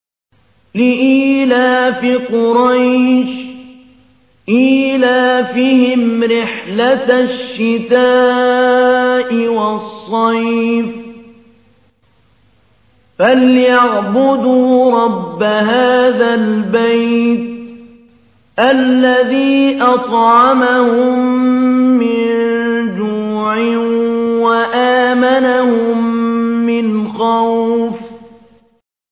تحميل : 106. سورة قريش / القارئ محمود علي البنا / القرآن الكريم / موقع يا حسين